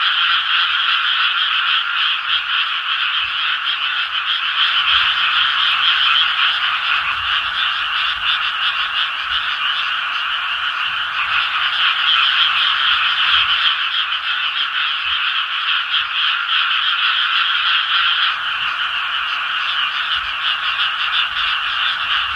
frog-chorus.mp3